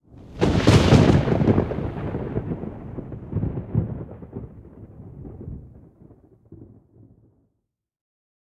thunder_7.ogg